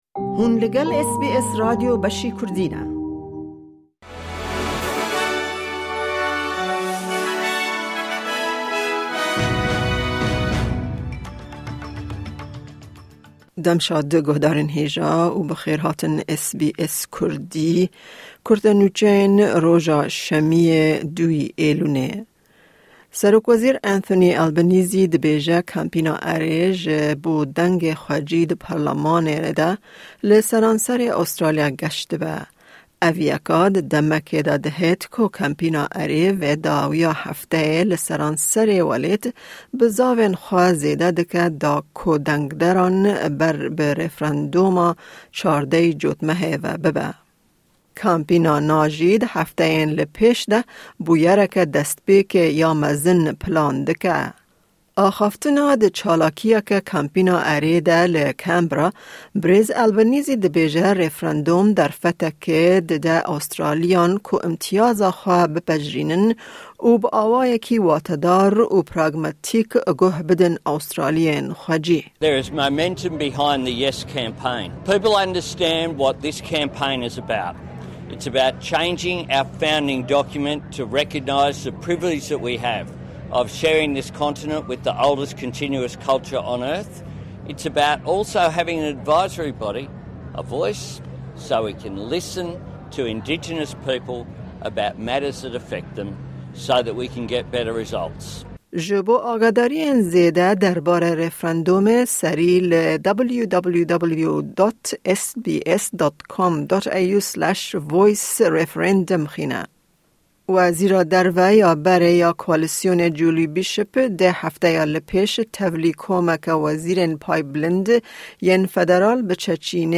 Newsflash